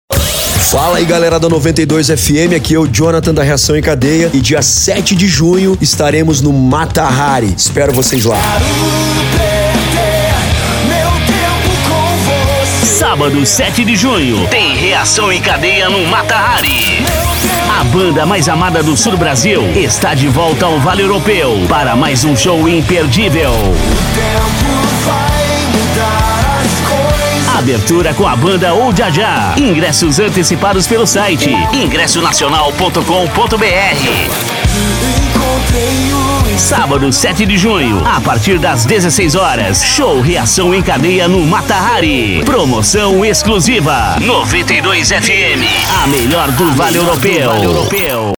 CHAMADA SHOW NACIONAL REAÇÃO EM CADEIA MATAHARI:
Impacto
Animada